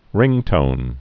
(rĭngtōn)